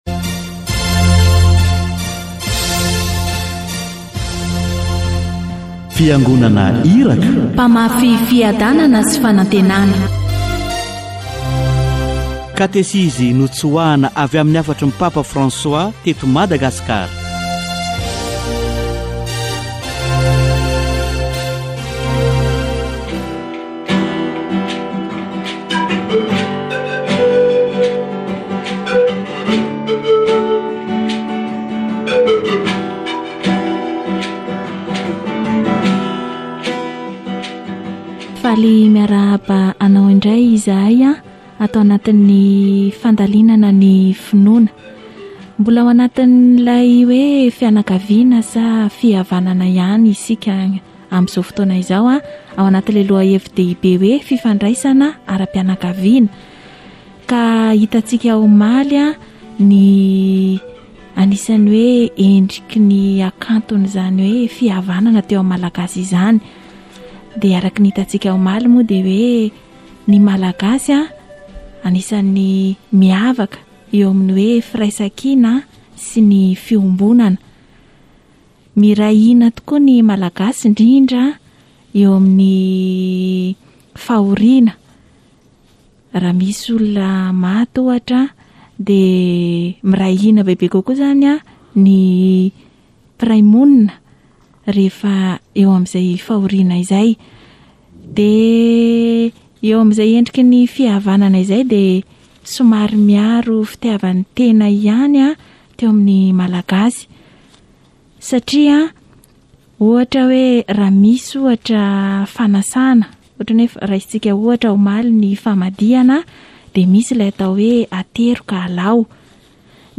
Katesizy momba ny fianakaviana